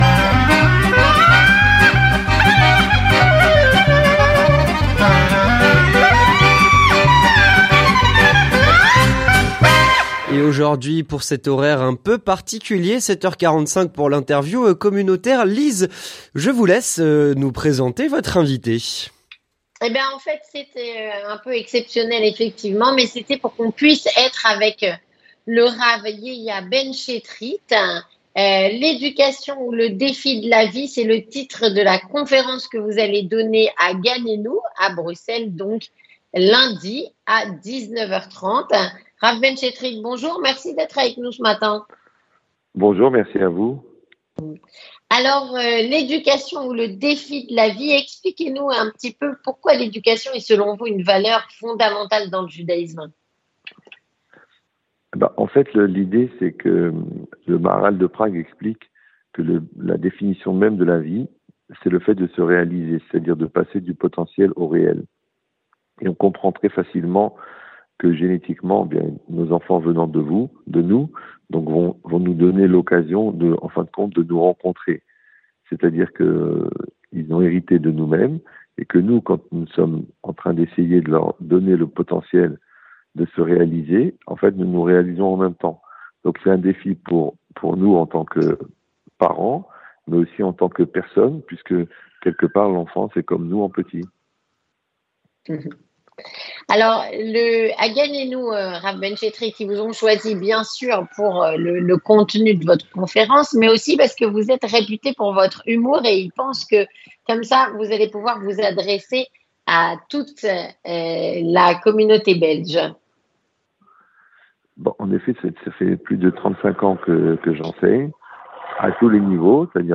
L'interview Communautaire